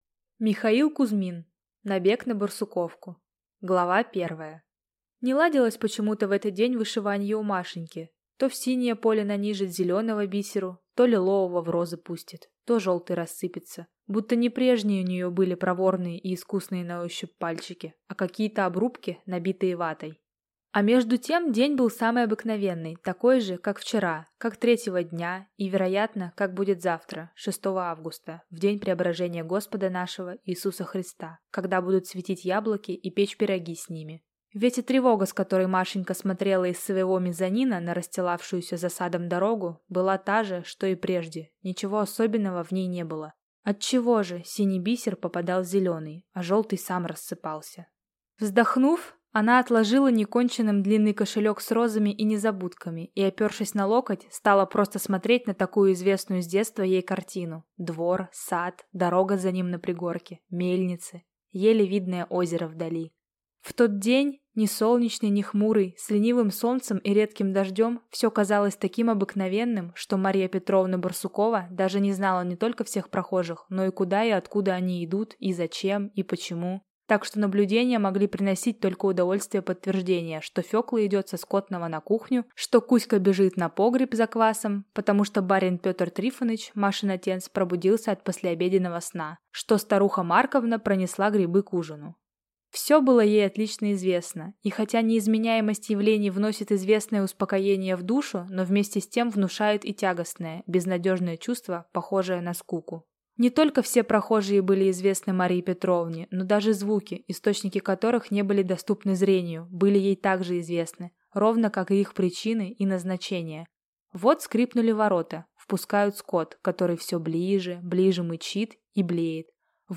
Аудиокнига Набег на Барсуковку | Библиотека аудиокниг